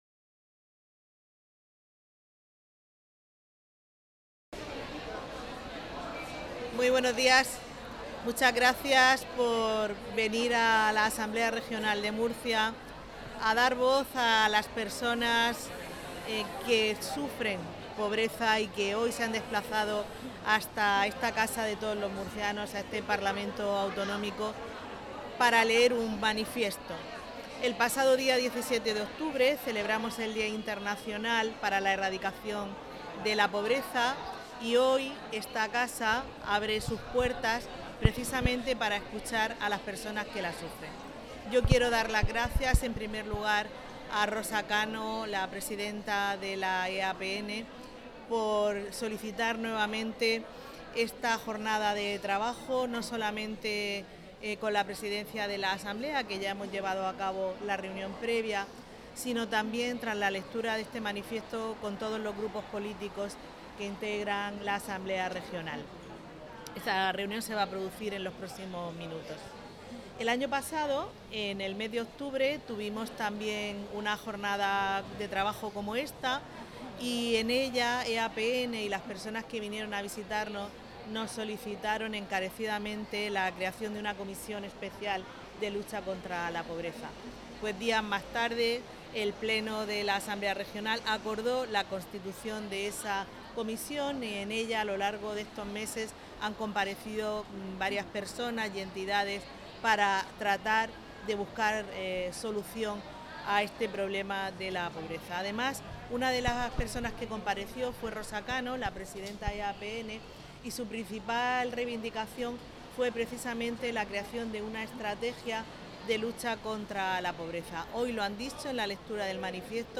• Declaraciones de la presidenta de la Asamblea Regional, Visitación Martínez